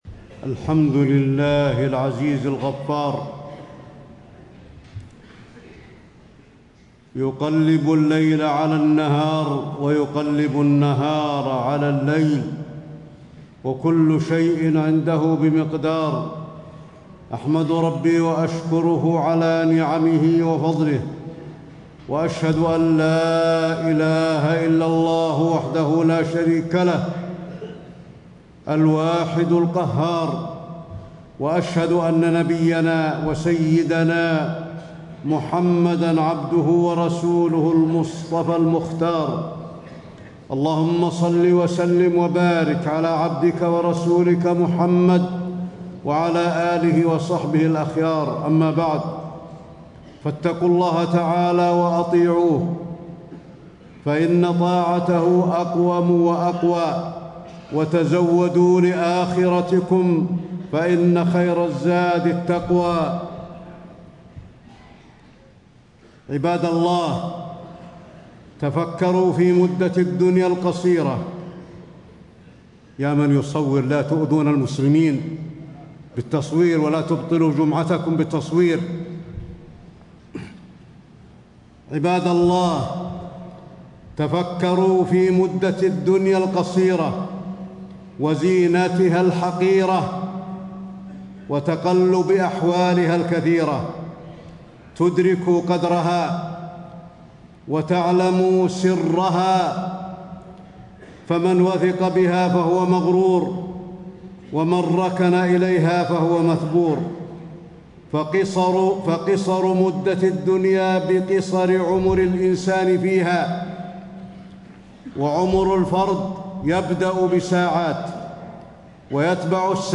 تاريخ النشر ٢٣ ذو الحجة ١٤٣٥ هـ المكان: المسجد النبوي الشيخ: فضيلة الشيخ د. علي بن عبدالرحمن الحذيفي فضيلة الشيخ د. علي بن عبدالرحمن الحذيفي التفكر في الحياة الدنيا